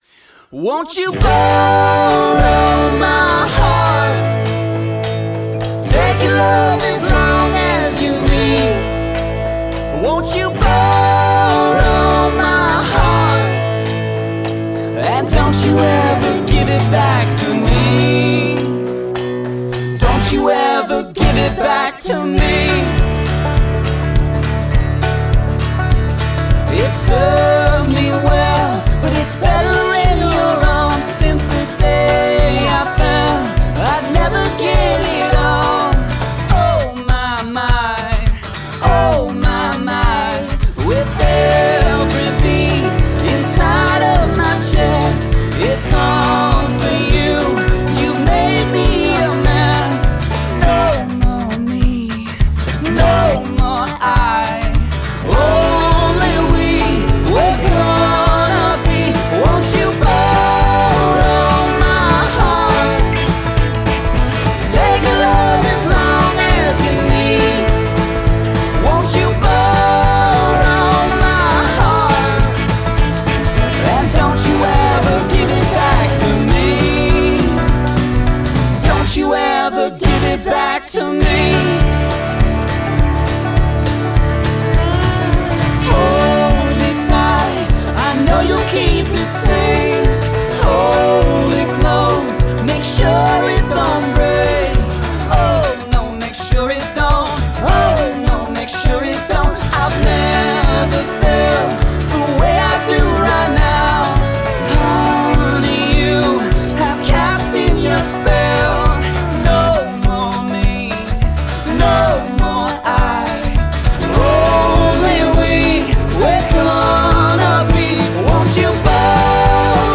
Songs by other cast members